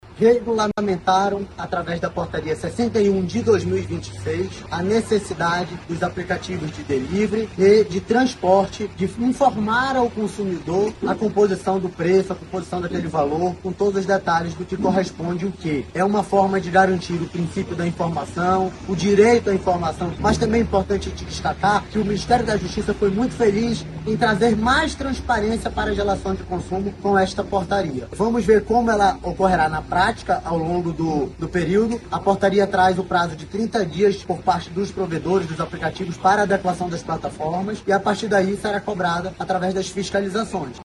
O diretor-presidente do Procon-AM, Jalil Fraxe, destaca a importância da nova regra.